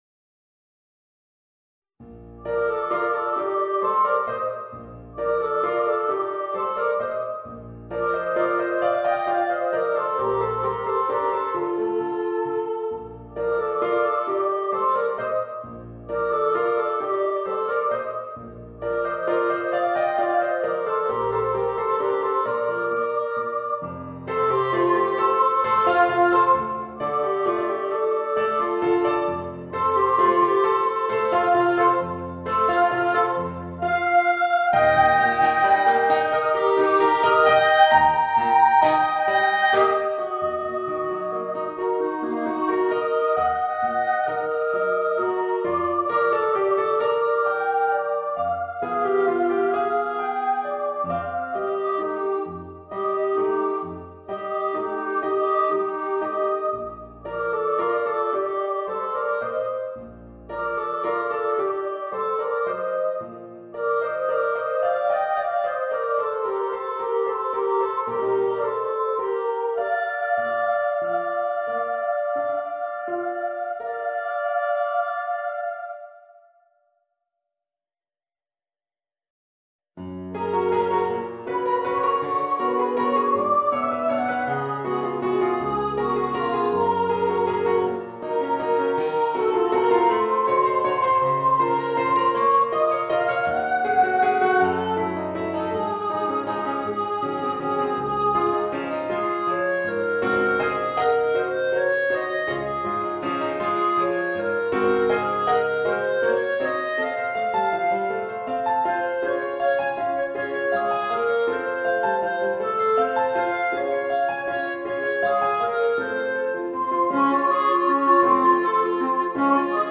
Voicing: Soprano, Clarinet and Piano